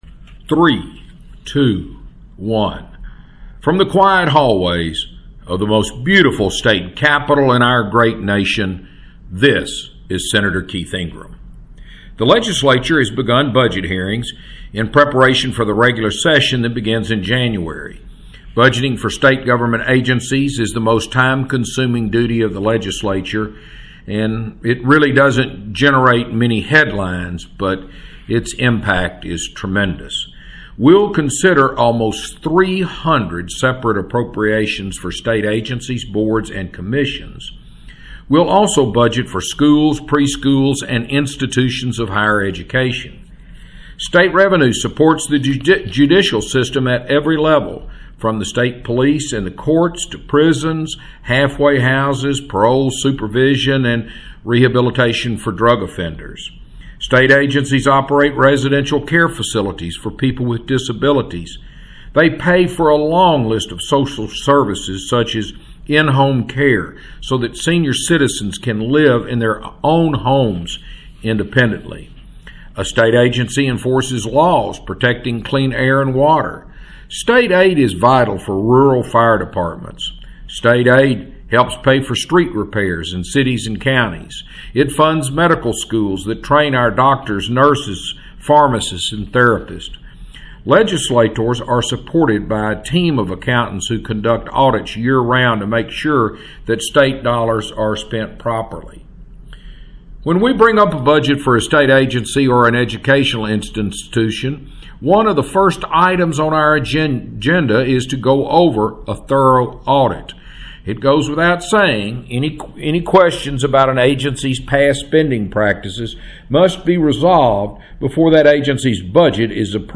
Weekly Address – October 16, 2020 | 2020-10-15T01:15:45.182Z | Sen. Keith Ingram | 2020-10-15T01:15:45.182Z | Sen.